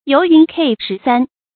尤云殢雪 注音： ㄧㄡˊ ㄧㄨㄣˊ ㄊㄧˋ ㄒㄩㄝˇ 讀音讀法： 意思解釋： 猶尤云殢雨。